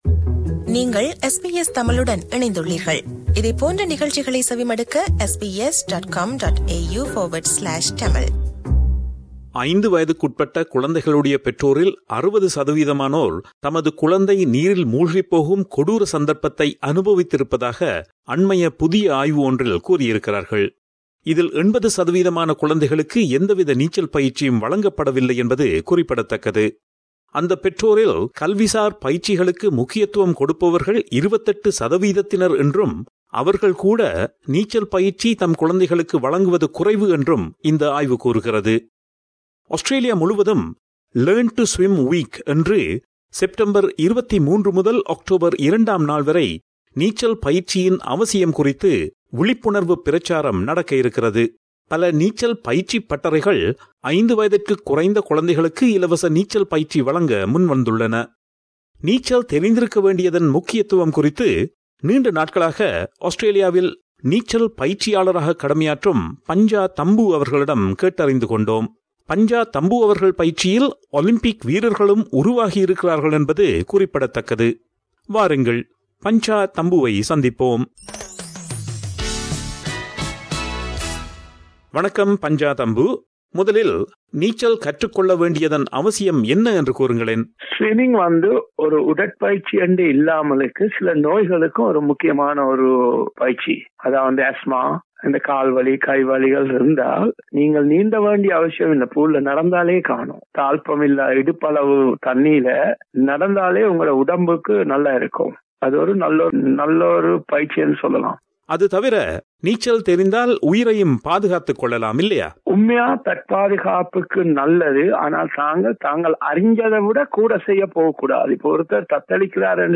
a veteran swimming coach